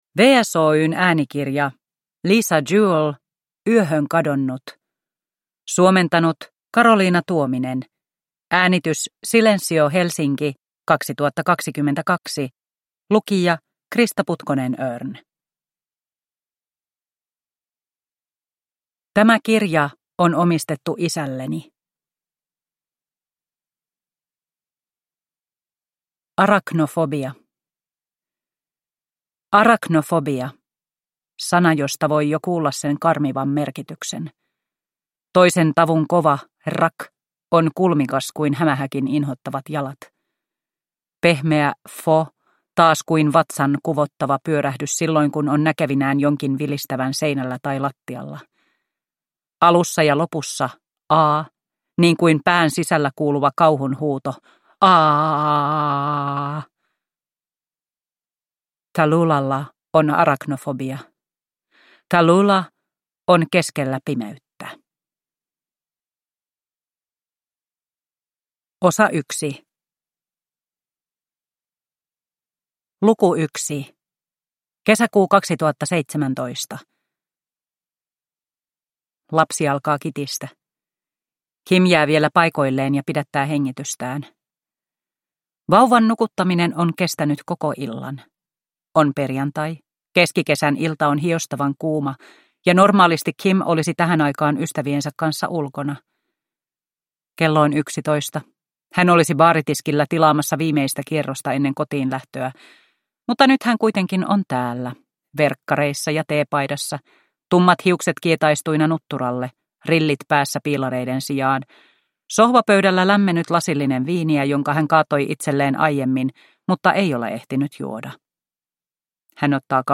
Yöhön kadonnut – Ljudbok – Laddas ner